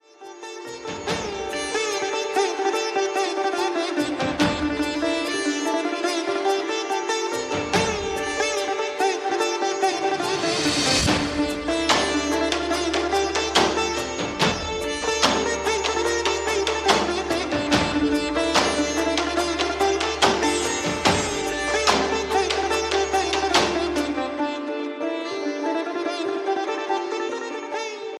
party ringtonedance ringtonekollywood ringtonetamil ringtone